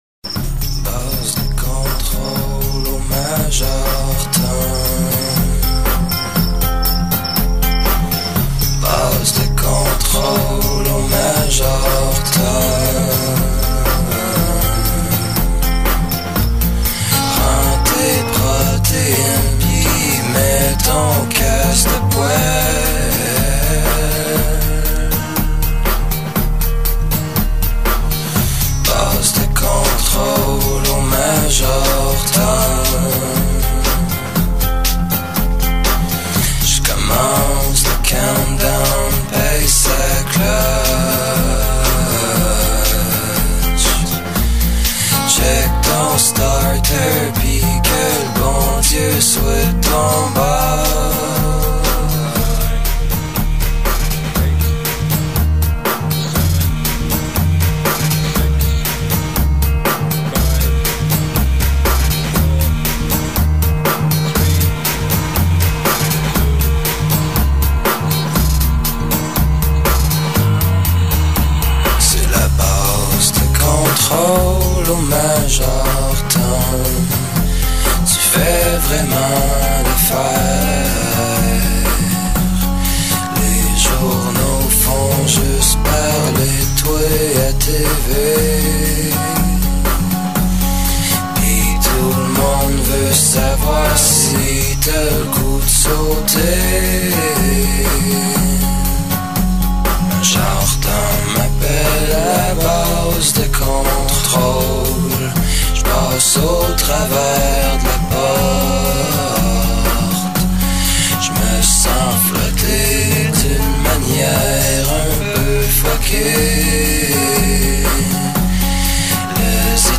une vision mélancolique de la chanson originale